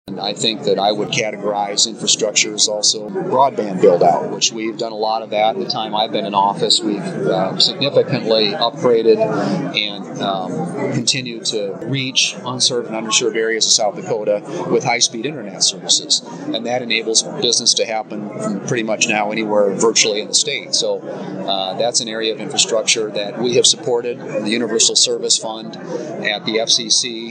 WATERTOWN, S.D.(KXLG)- U.S. Senator John Thune visited Watertown Thursday to address the local Rotary Club during their meeting held at the Elks Lodge.